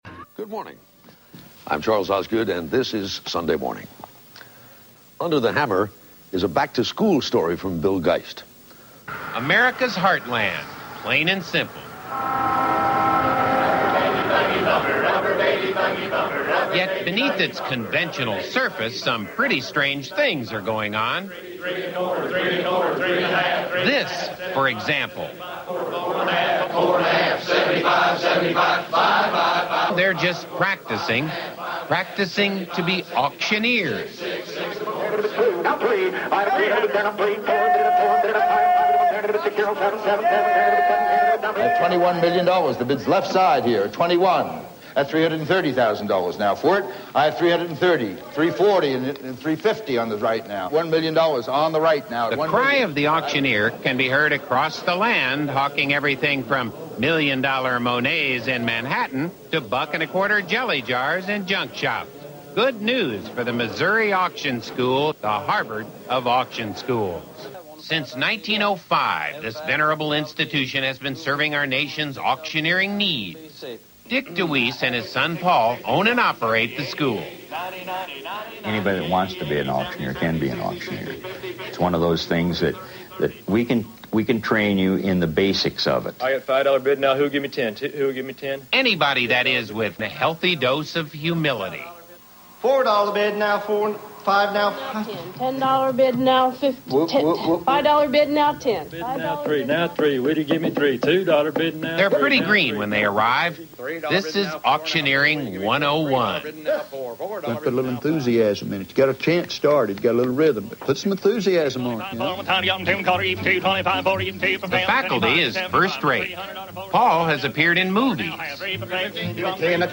CBS Sunday Morning with Humorist Bill Geist